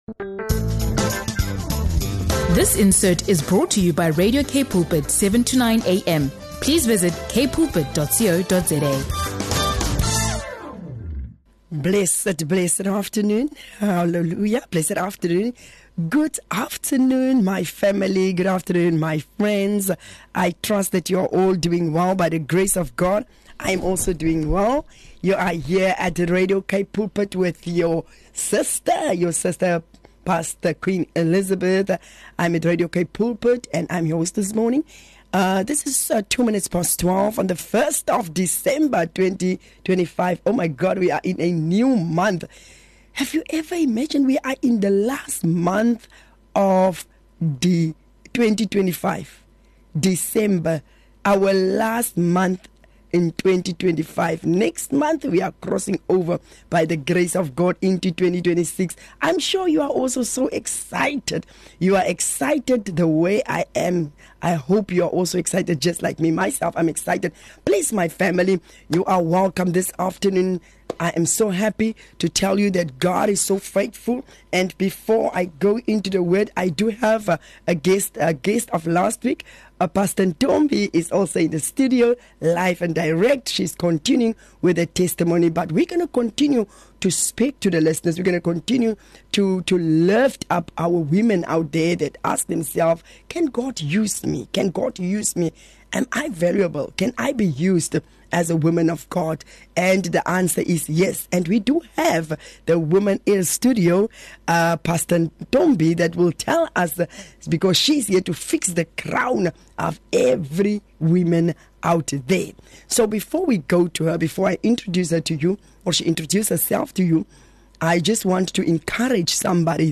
Tune in for a conversation that will ignite your faith, heal your heart, and awaken your calling.